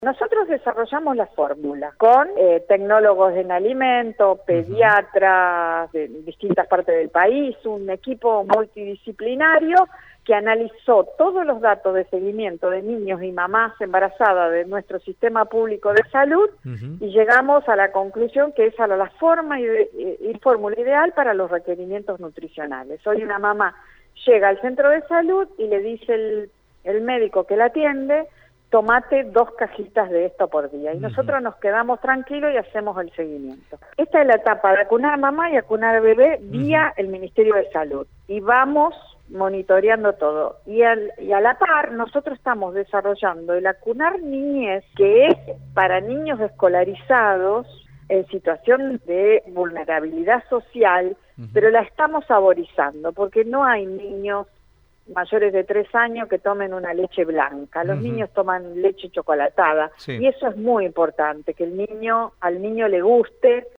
La ministra de Producción de la Provincia Alicia Ciciliano sostuvo a través de Radio EME, que el alimento se elaboró en la planta de Tregar, en Gobernador Crespo y adelantó que pronto será el momento de fabricar el alimento a base de leche «saborizada» para niños en edad escolar.